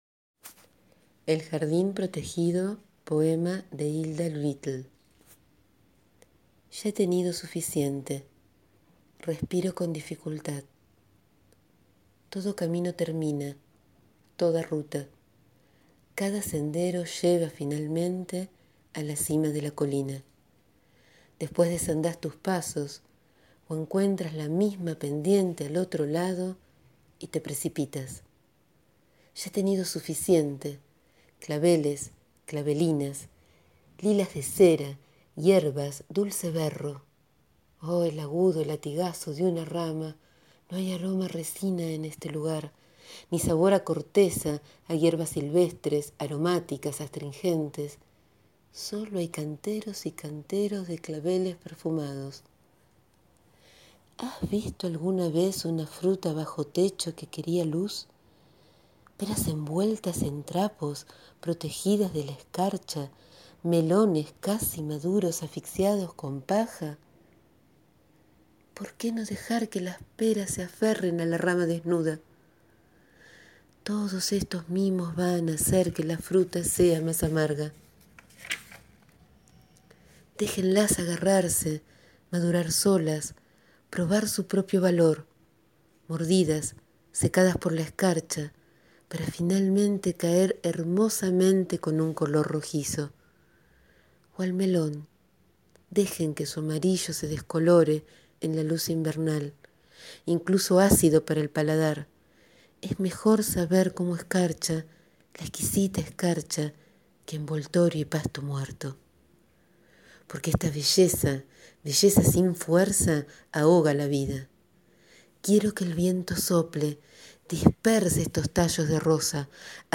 Hoy les leo el poema «El jardín protegido» de Hilda Doolittle, del libro «Qué son las islas» de Editorial Llantén.